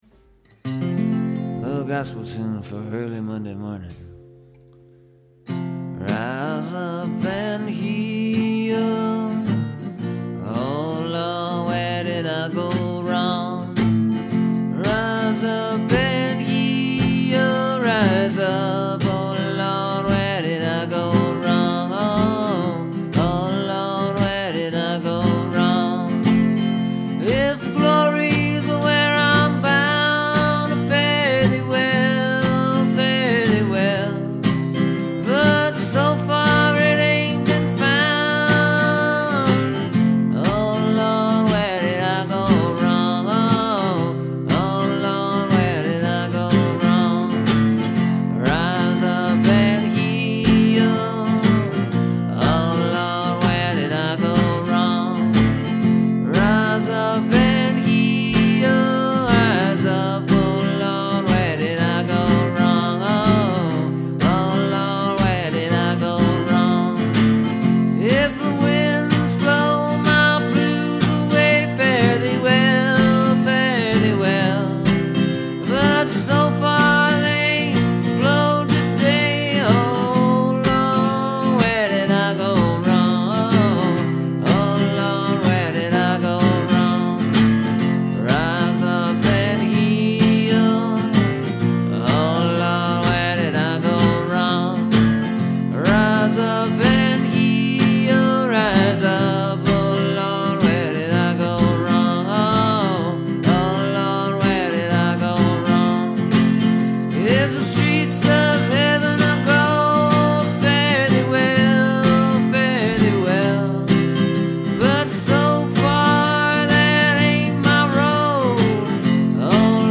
Here’s a new song based on a Gospel tune called “Ride On King Jesus.”
Now, I’m not saying I sing it anything like that, but it’s along the same lines.
I had to add a few more differing verses, though, cause I don’t have 20 people behind and around and above me all shoutin out loud.